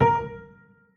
BFlat.wav